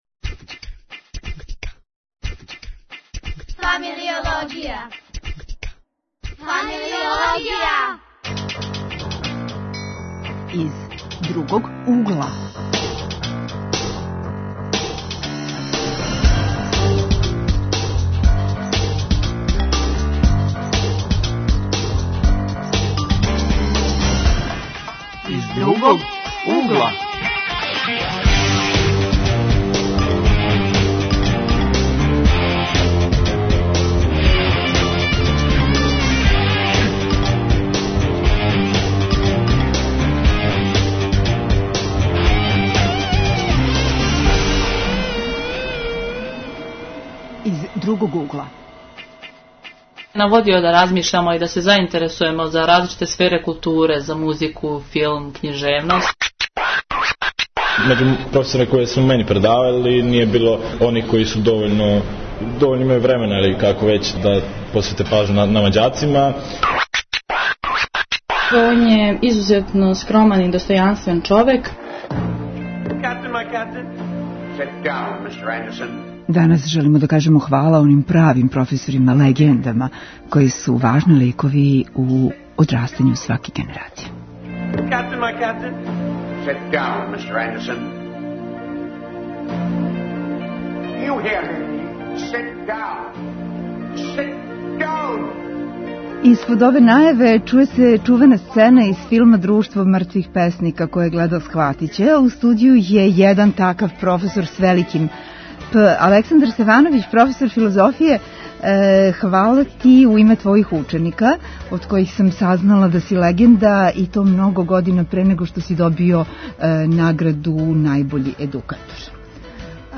Гости: средњошколци